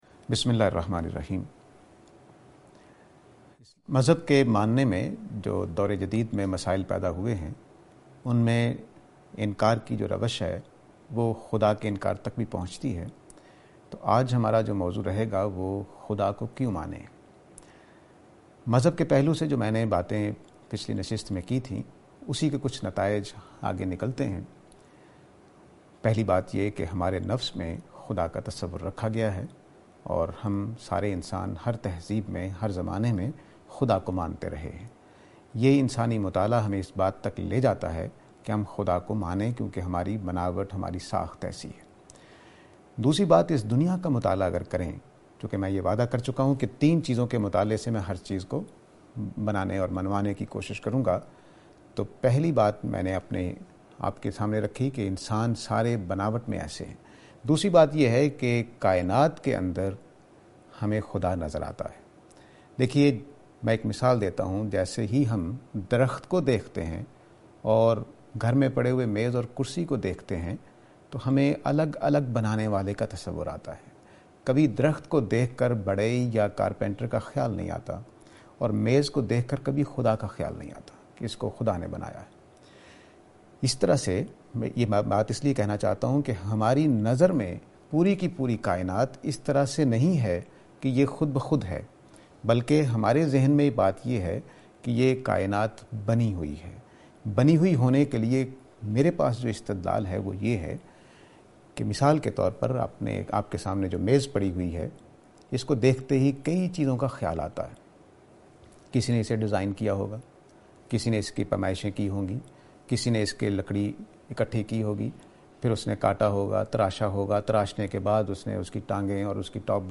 This lecture is and attempt to answer the question "Why God?".